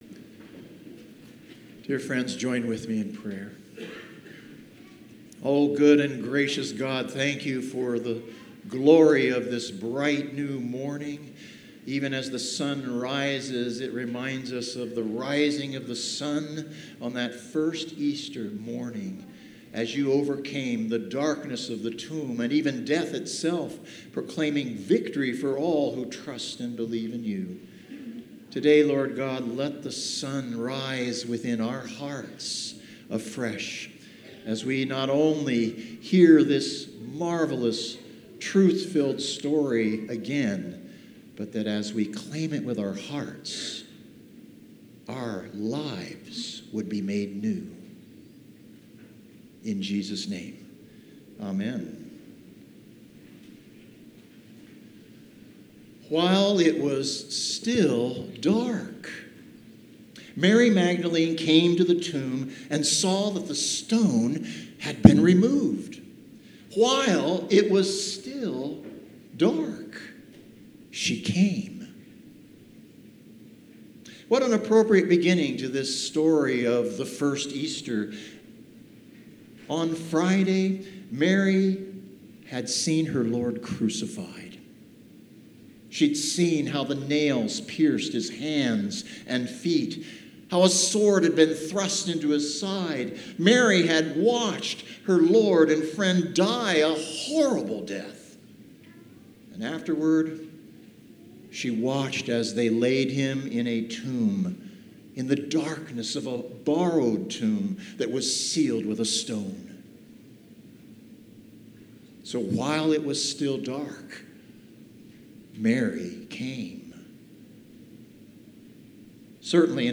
Sermons | Moe Lutheran Church